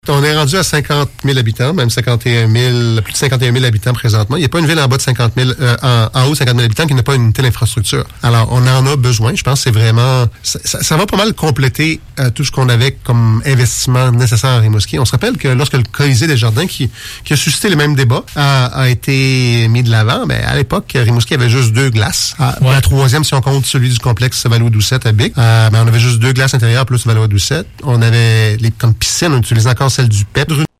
Monsieur Caron a expliqué sur nos ondes que la croissance de la taille de Rimouski, l’arrivée d’étudiants en médecine et en médecine vétérinaire et les besoins des jeunes familles justifient la décision des élus.